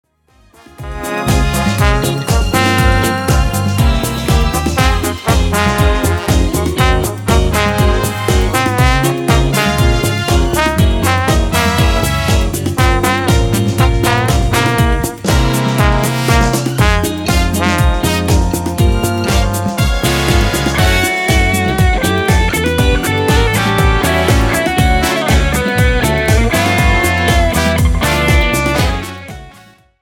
POP  (03.21)